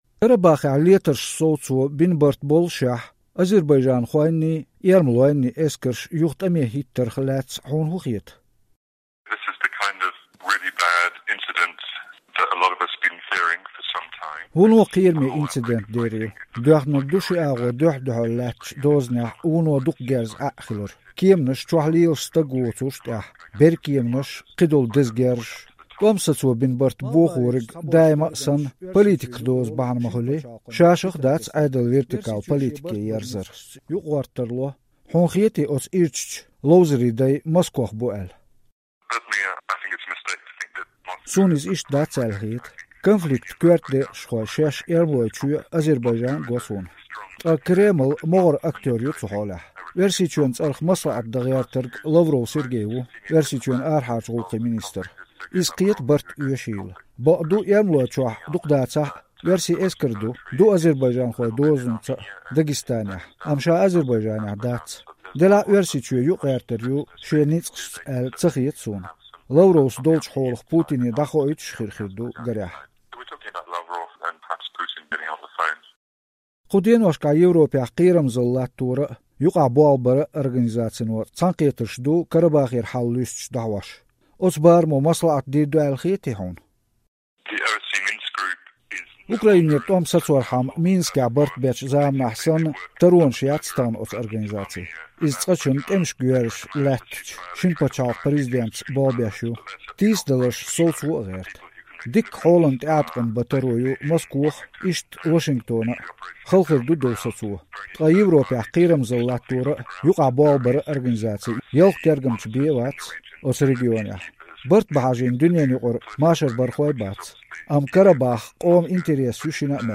Карнеги Фондан векалера, Кавказ зерехь тоьлла говзанча волчу британхочуьнгара Де Ваал Томасера Маршо Радион Азербайджанан сервисо эцна интервью.